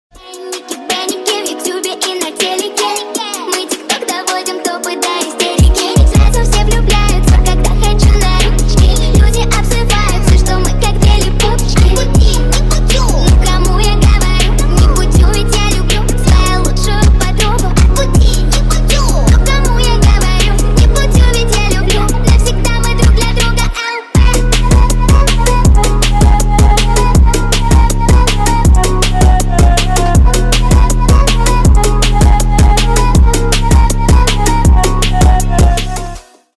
Громкие Рингтоны С Басами
Фонк Рингтоны
Рингтоны Ремиксы » # Поп Рингтоны